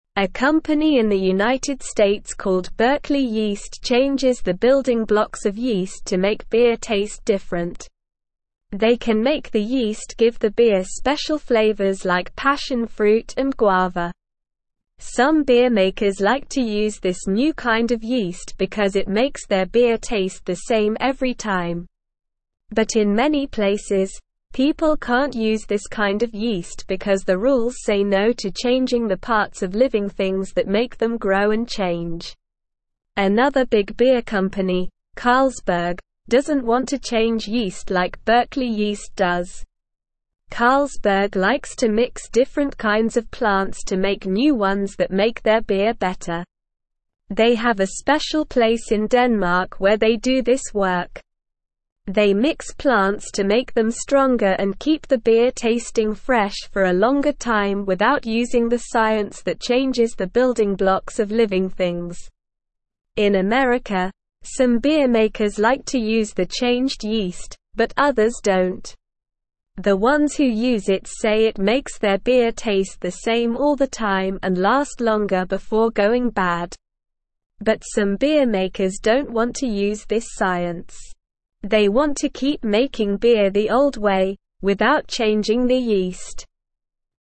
Slow
English-Newsroom-Lower-Intermediate-SLOW-Reading-Changing-Yeast-to-Make-Beer-Taste-Different.mp3